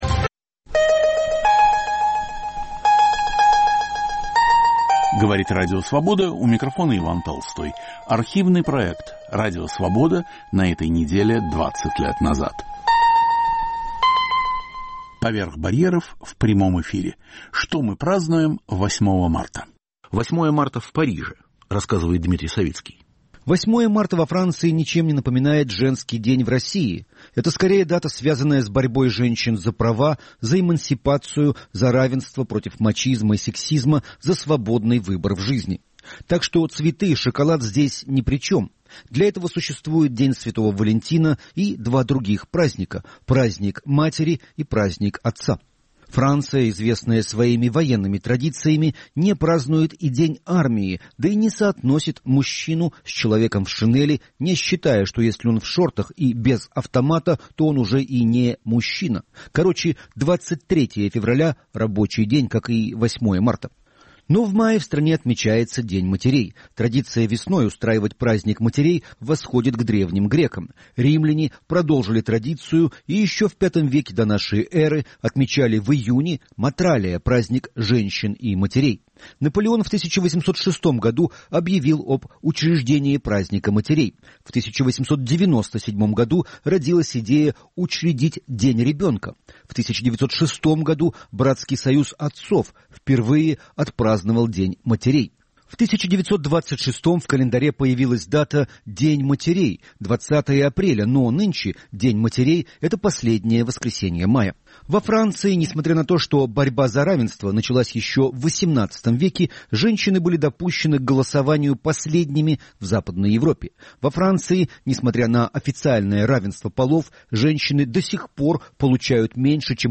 "Поверх барьеров" в прямом эфире. Что мы празднуем 8 марта